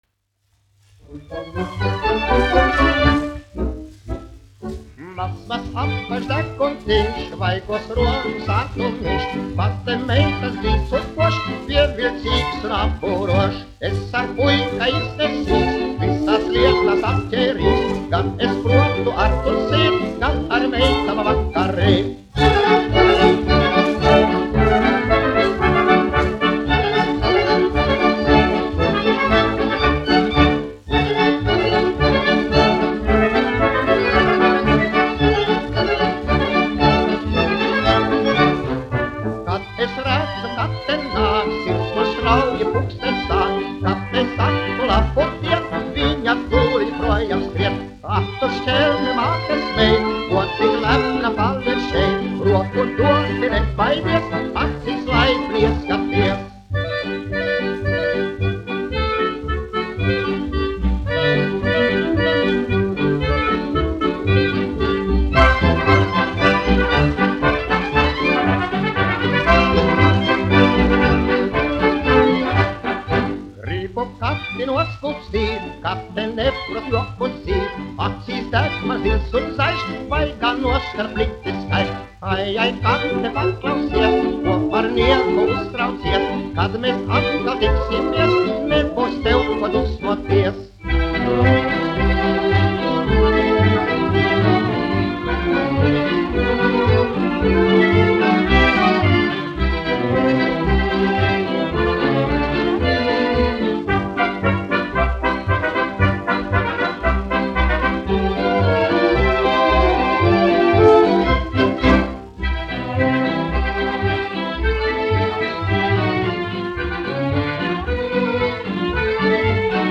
1 skpl. : analogs, 78 apgr/min, mono ; 25 cm
Polkas
Populārā mūzika -- Latvija
Skaņuplate